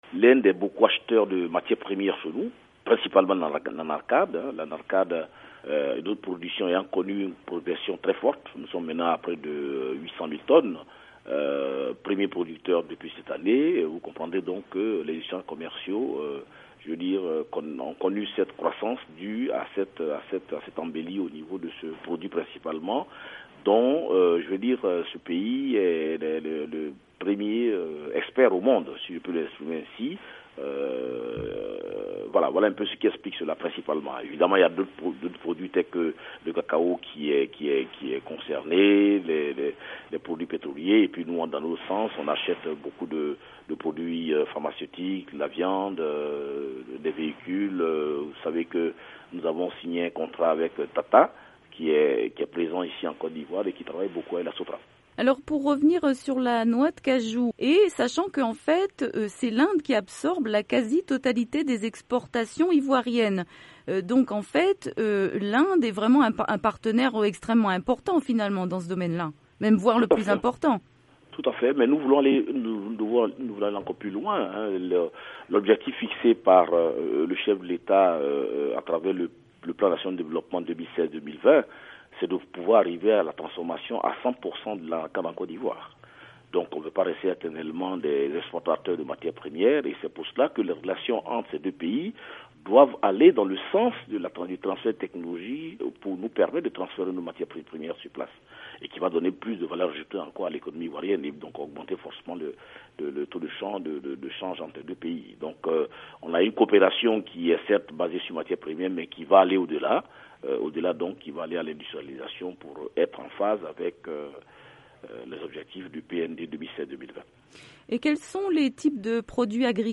Esmel Emmanuel Essis joint à Abidjan